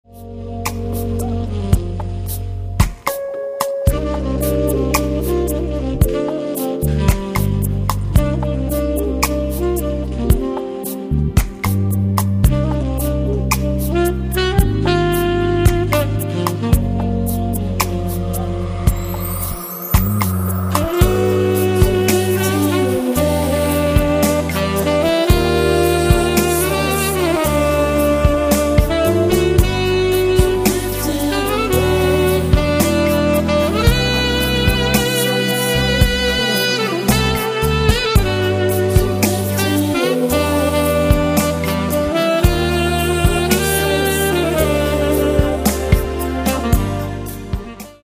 Saxophon & coole Sounds
Tenor-Saxophon